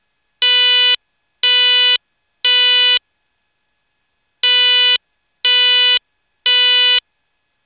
Life safety notification appliances
Code 3 Horn
Code 3 Tone (500 Hz ANSI 53, 41 Temporal Pattern)
amt-c3tone.WAV